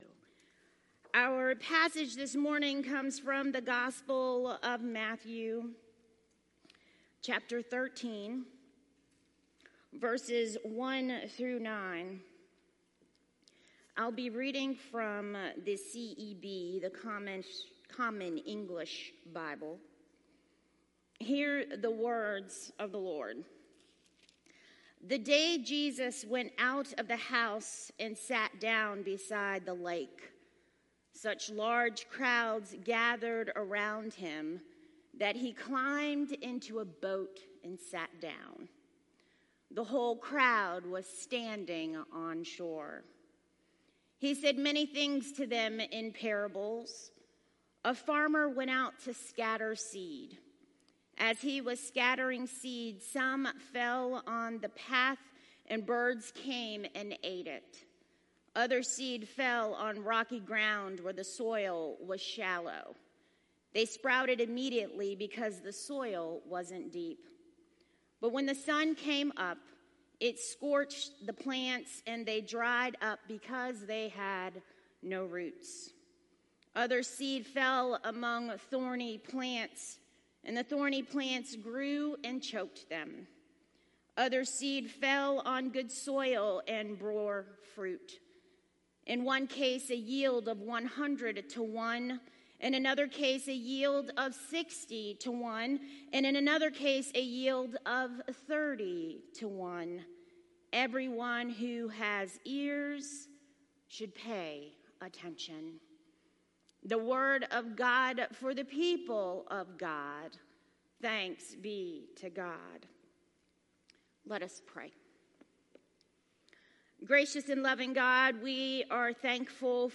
sermon-712.mp3